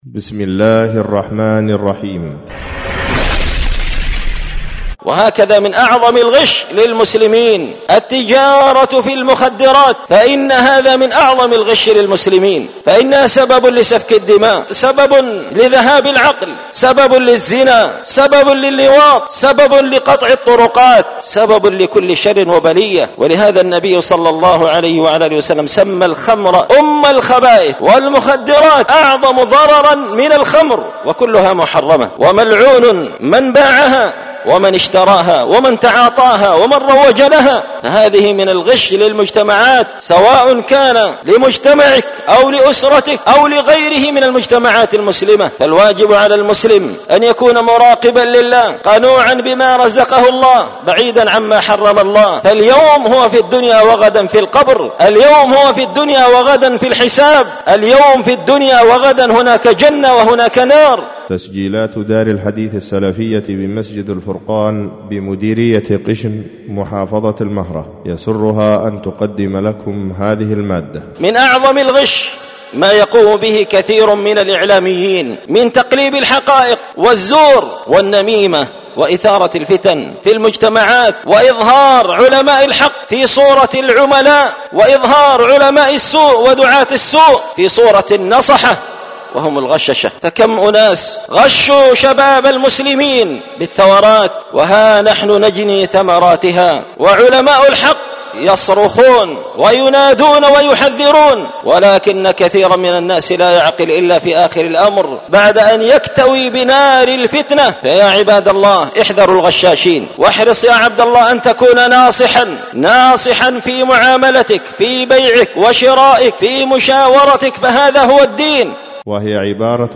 ألقيت هذه الخطبة بدار الحديث السلفية بمسجد الفرقان قشن-المهرة-اليمن تح…
خطبة جمعة بعنوان: